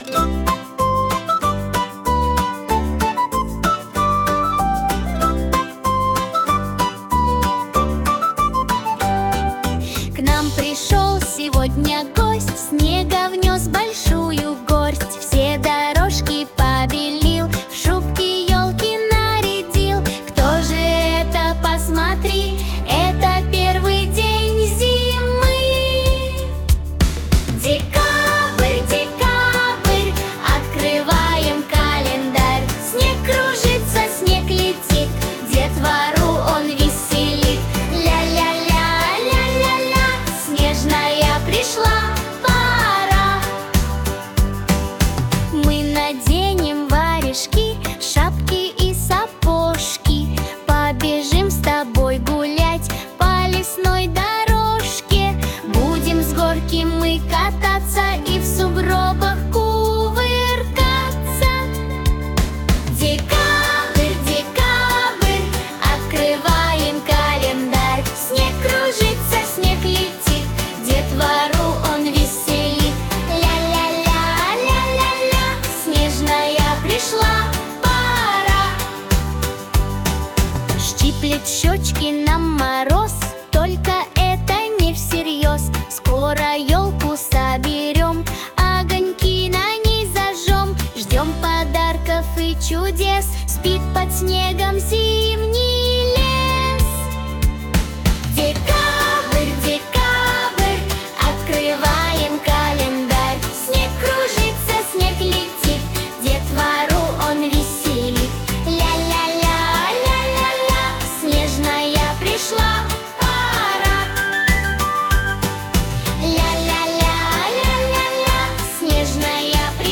• Качество: Хорошее
• Категория: Детские песни
Простая, добрая и ритмичная песенка про декабрь.